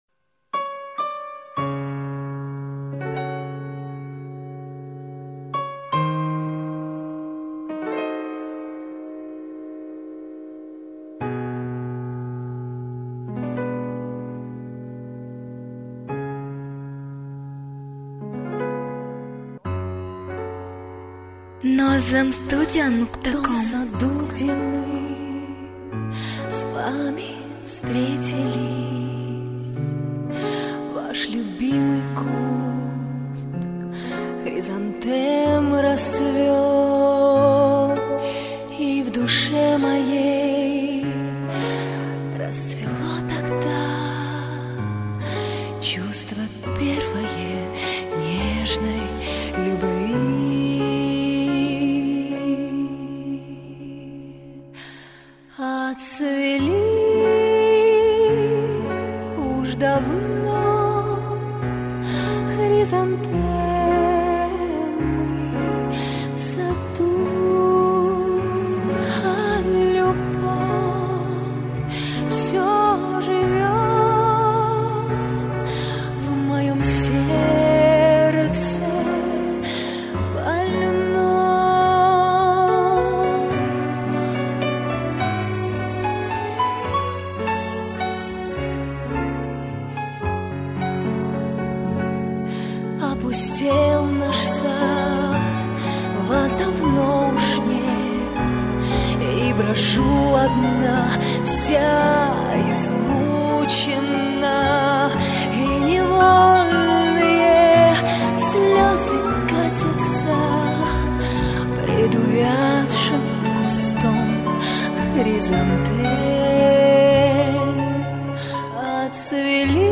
minus скачать мр3 2022